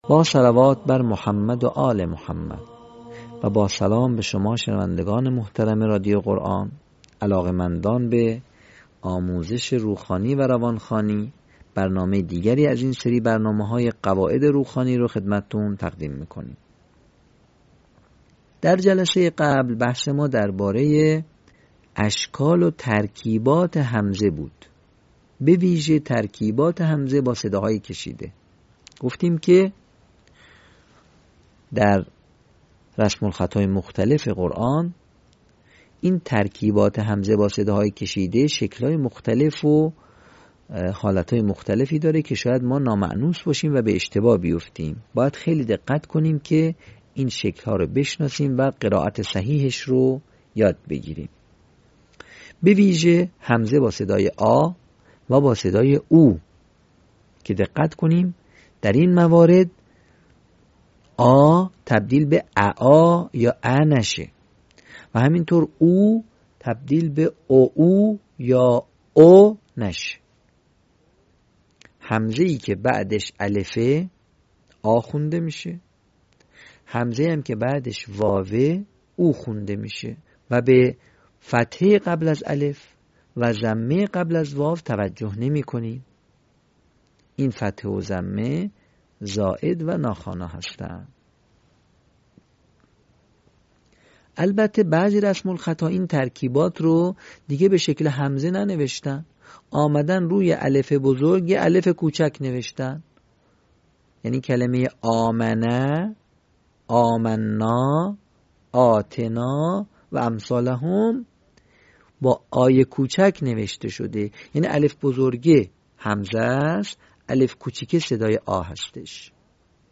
صوت | آموزش روخوانی «صداهای کشیده»